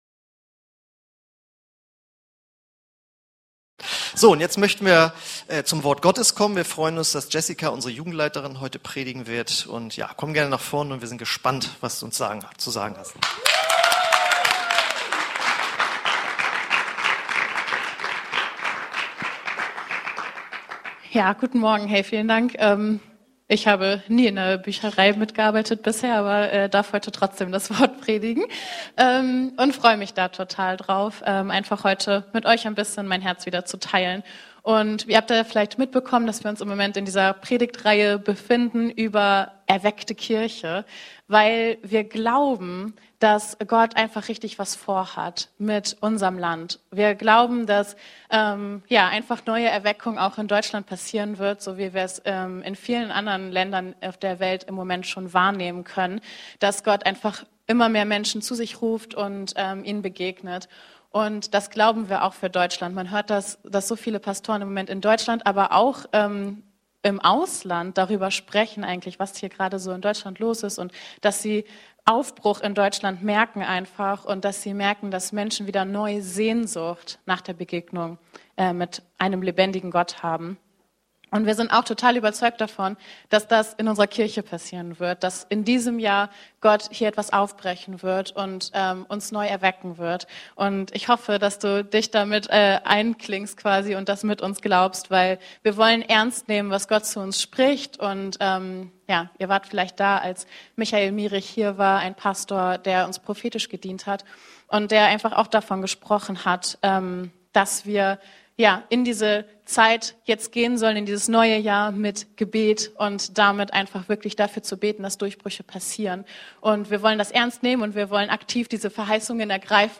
Predigten (v1) – OASIS Kirche